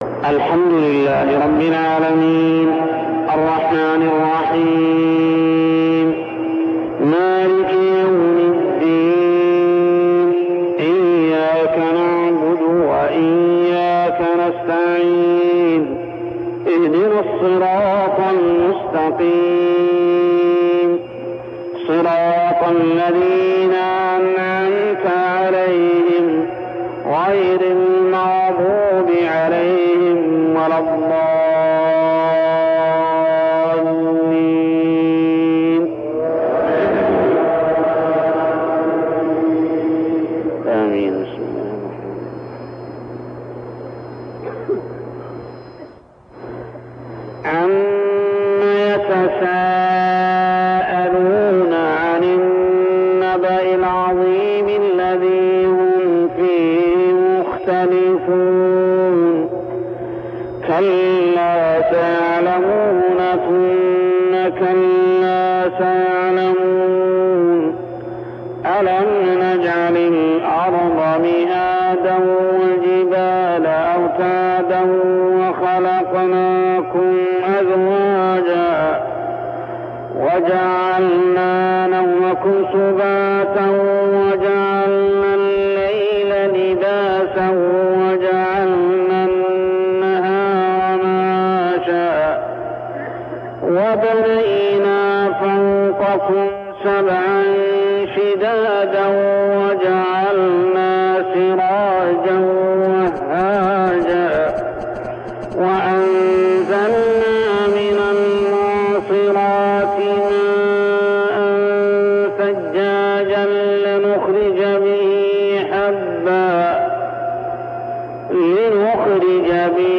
تلاوة من صلاة الفجر لسورة النبإ كاملة عام 1402هـ | Fajr prayer Surah Al-Naba > 1402 🕋 > الفروض - تلاوات الحرمين